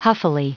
Prononciation du mot huffily en anglais (fichier audio)
Prononciation du mot : huffily